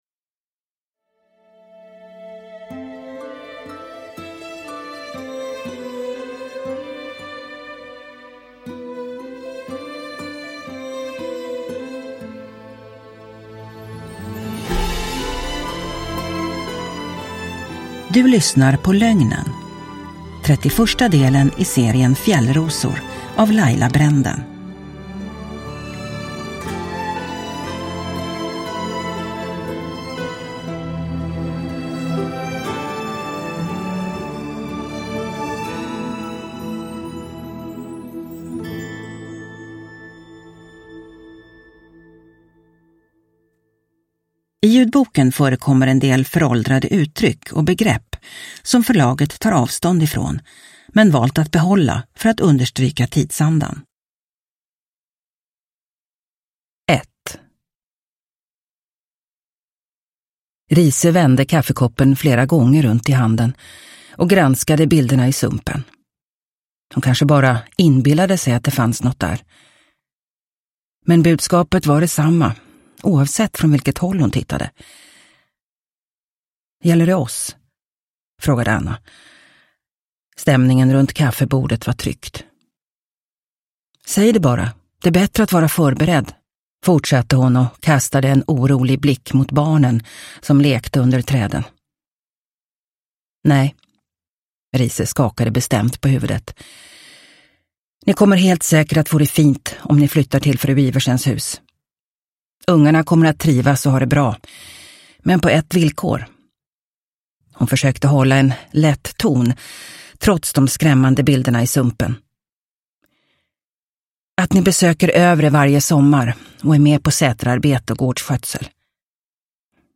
Lögnen – Ljudbok – Laddas ner